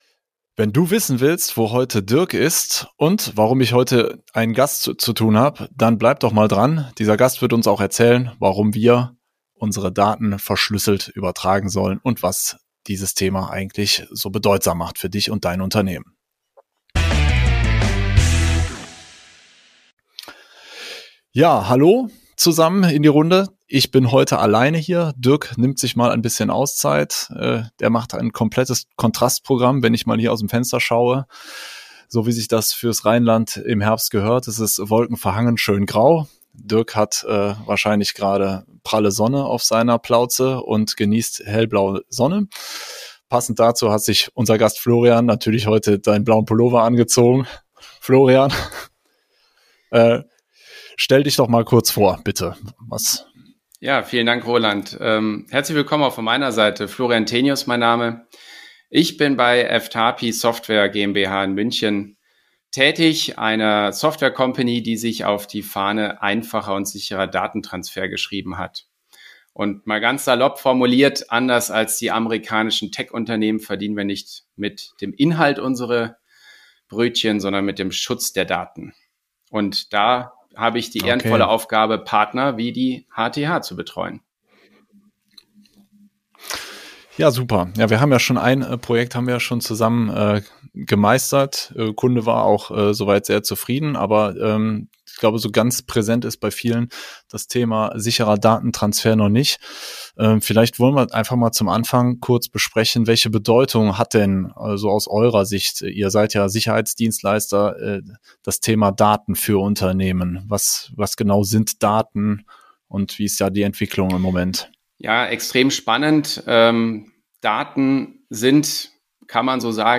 Interview - IT. Praktisch. Verständlich. - Der IT-Podcast für kleine und mittlere Unternehmen